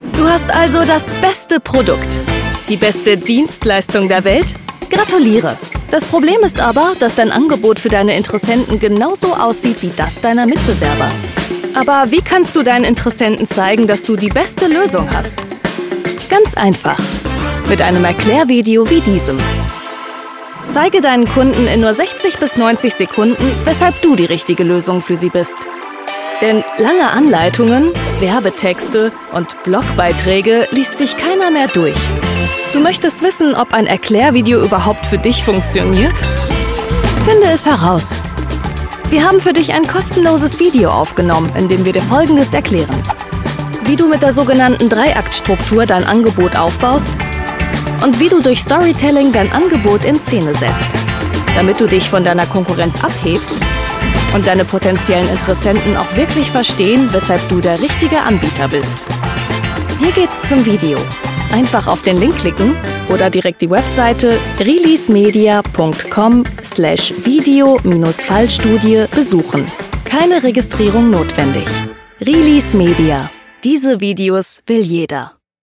Komplexes verständlich erzählt. Professionelle Sprecherin mit eigenem Studio.
Kein Dialekt
Sprechprobe: eLearning (Muttersprache):